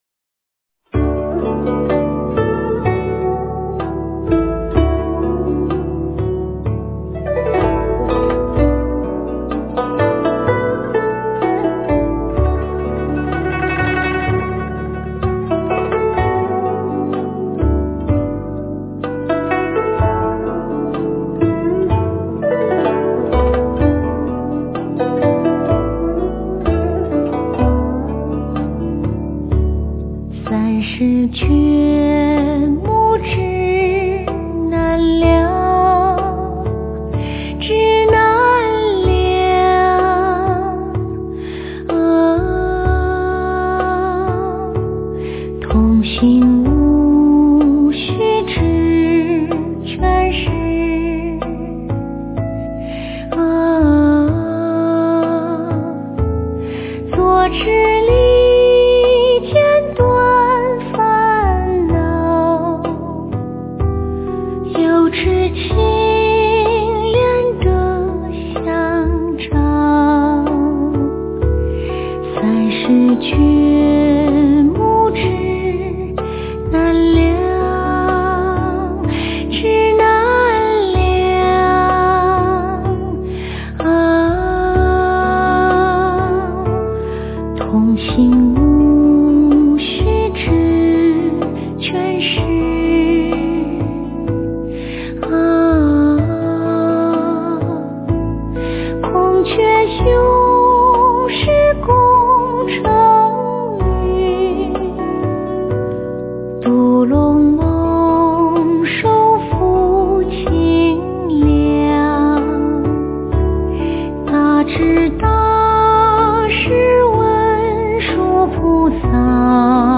文殊菩萨赞 - 诵经 - 云佛论坛
文殊菩萨赞 诵经 文殊菩萨赞--观音菩萨在心中 点我： 标签: 佛音 诵经 佛教音乐 返回列表 上一篇： 大悲咒-笛子版 下一篇： 忏悔文 相关文章 金光明经-舍身品第十七 金光明经-舍身品第十七--未知...